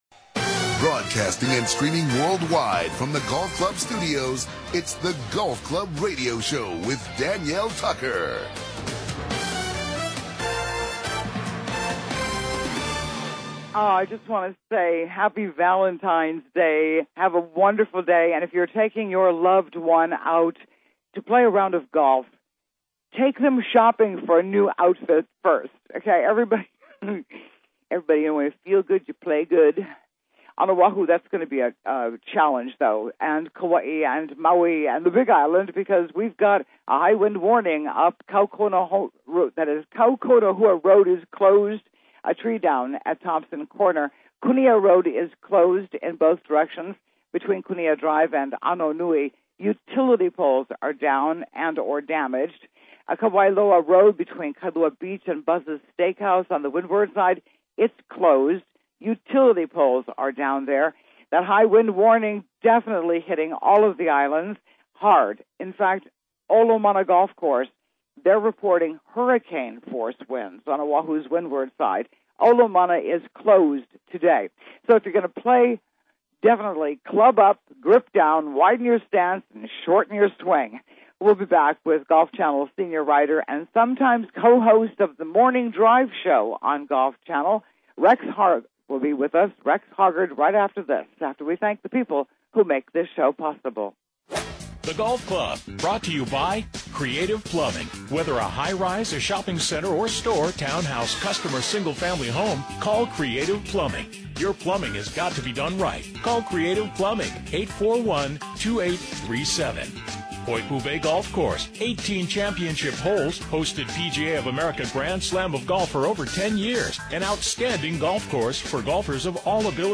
Live on Saturday